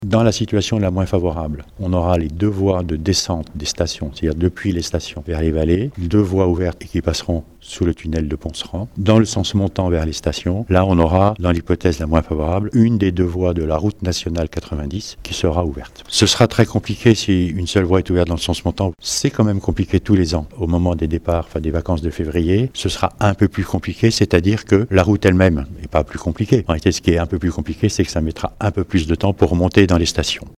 François Ravier le préfet de la Savoie nous rappelle l’hypothèse la moins favorable envisagée pour le moment :